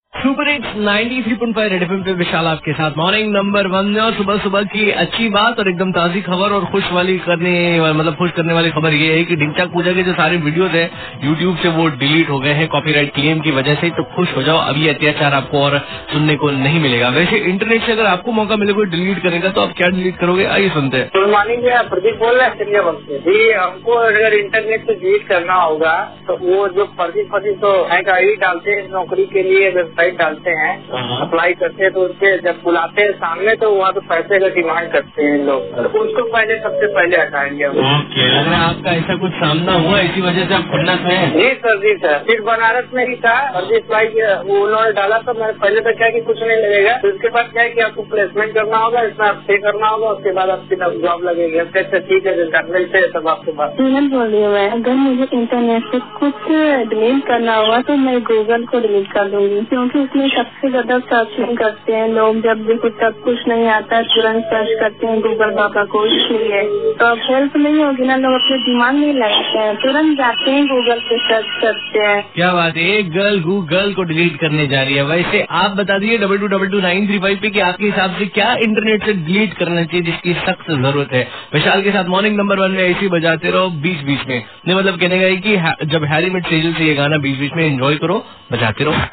CALLER BYTE ABOUT INTERNET